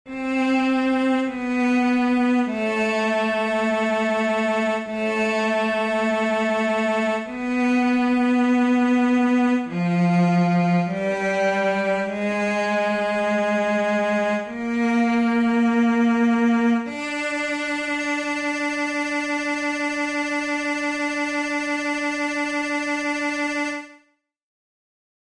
How many parts: 8
Type: Barbershop
Each recording below is single part only.
Other part 3: